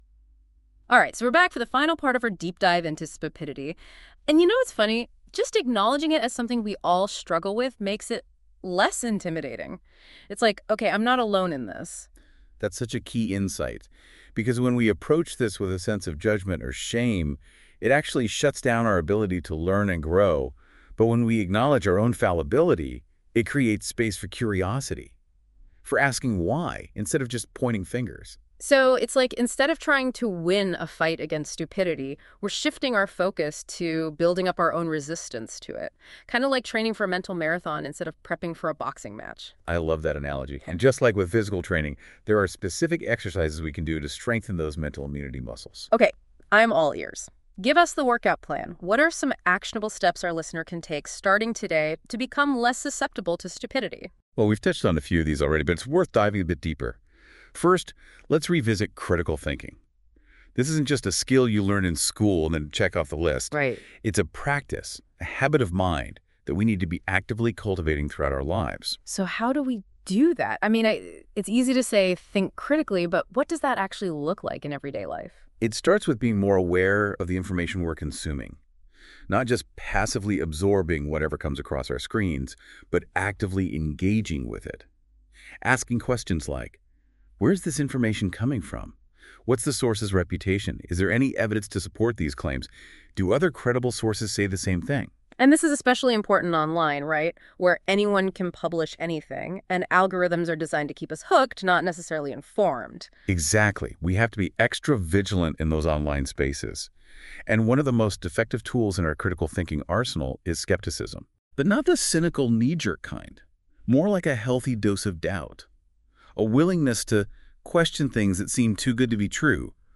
This is where your ideas take center stage, transformed into captivating audio experiences with the help of Google’s cutting-edge AI.